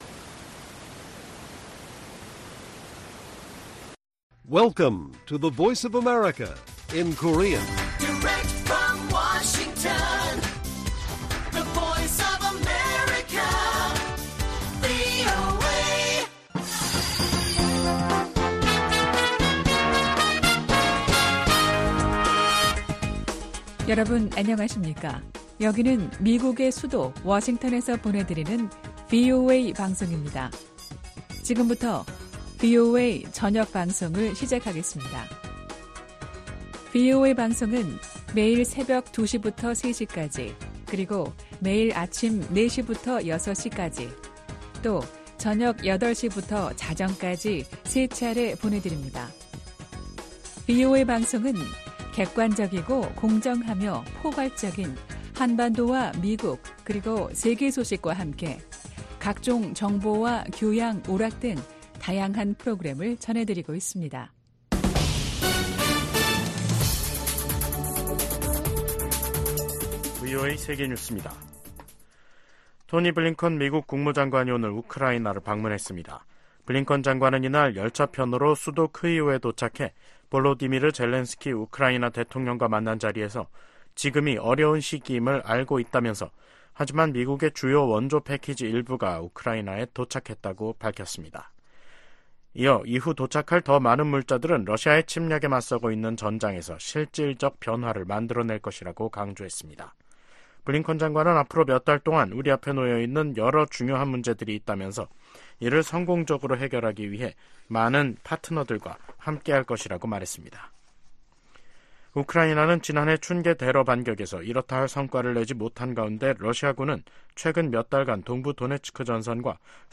VOA 한국어 간판 뉴스 프로그램 '뉴스 투데이', 2024년 5월 14일 1부 방송입니다. 러시아가 철도를 이용해 북한에 유류를 수출하고 있다는 민간 기관 분석이 나온 가운데 실제로 북러 접경 지역에서 최근 열차 통행이 급증한 것으로 나타났습니다. 러시아가 올해 철도를 통해 25만 배럴의 정제유를 북한에 수출했다는 분석이 나온 데 대해 국무부가 북러 협력 심화에 대한 심각한 우려를 나타냈습니다.